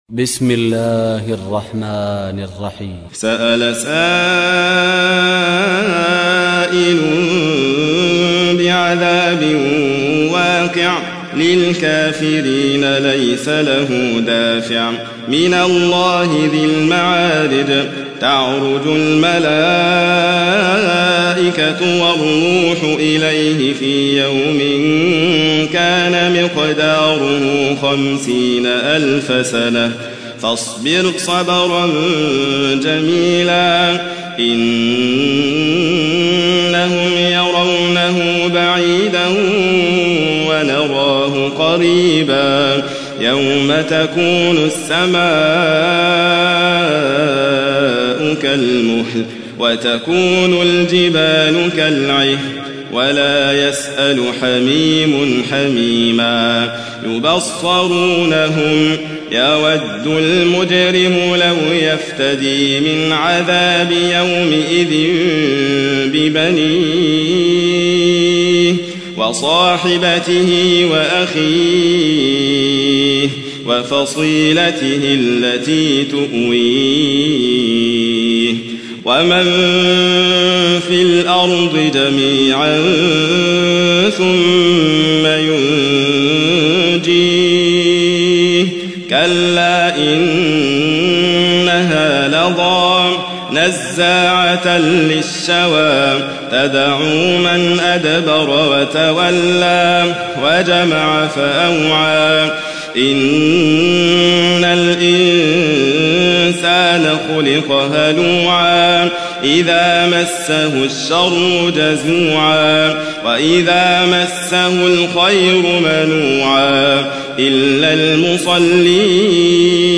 تحميل : 70. سورة المعارج / القارئ حاتم فريد الواعر / القرآن الكريم / موقع يا حسين